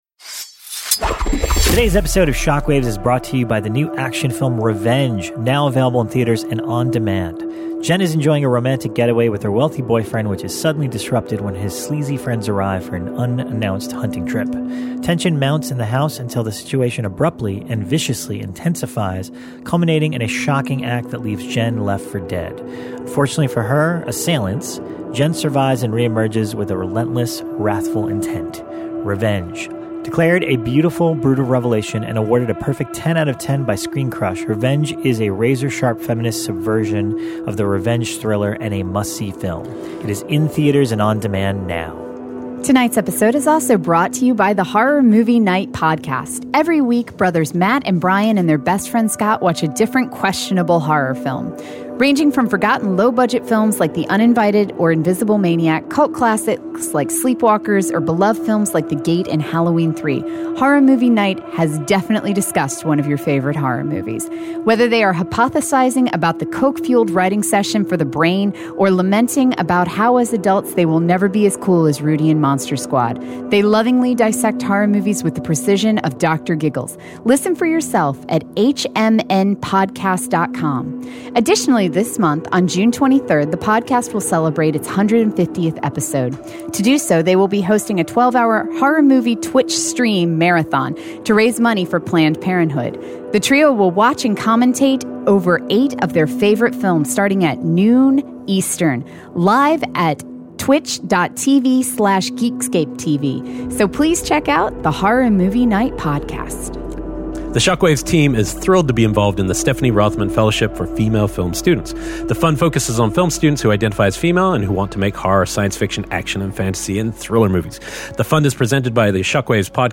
We talk video games in horror movies, and then horror video games! Kick back, grab your game controller and join the conversation!